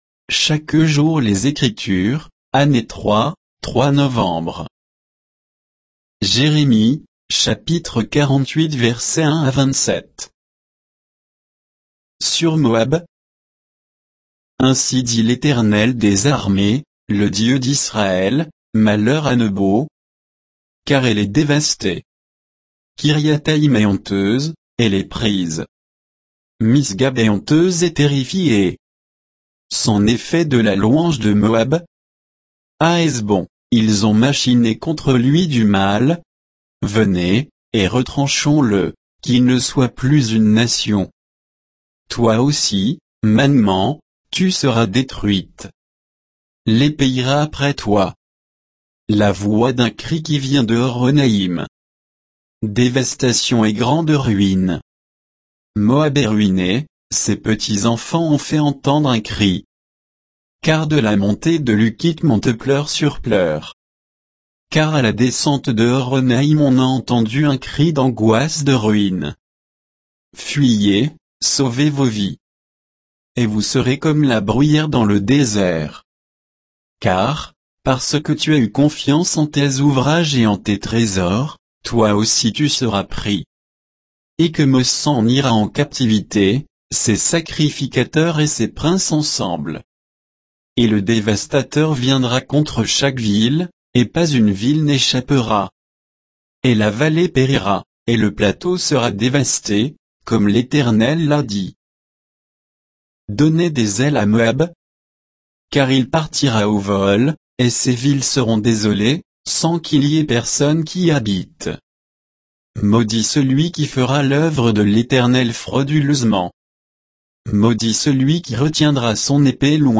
Méditation quoditienne de Chaque jour les Écritures sur Jérémie 48, 1 à 27